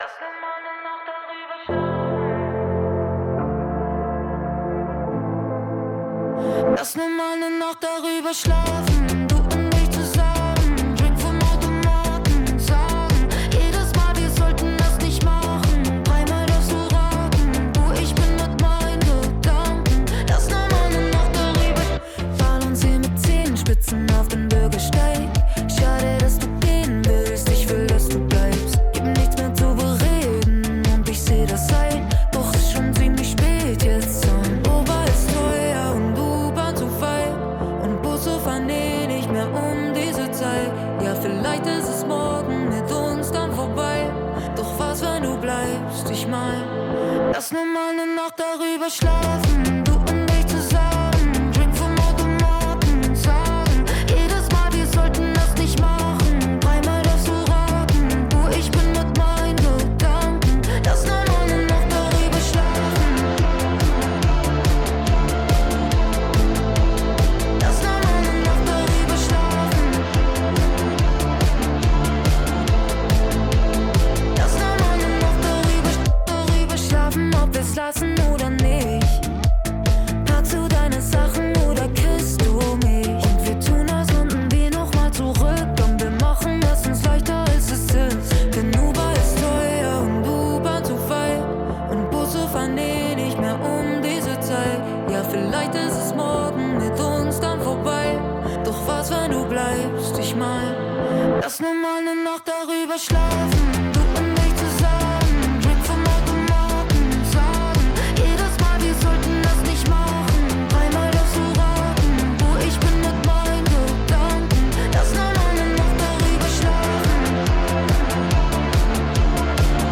(live)
* Track aus Video extrahiert.